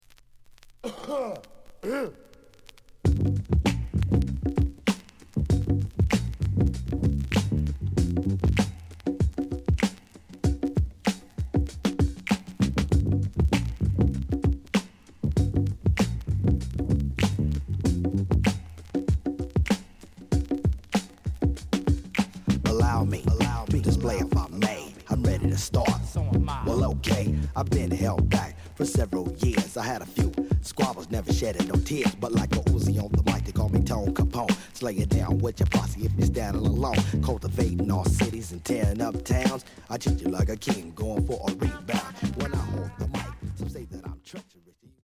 試聴は実際のレコードから録音しています。
●Genre: Hip Hop / R&B
●Record Grading: VG~VG+ (両面のラベルに若干のダメージ。)